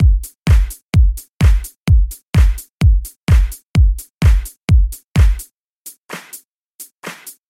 寒舍大鼓
描述：鼓声与我刚刚上传的钢琴和低音循环相配合，如果你使用，请发布链接，我很想听听你的作品。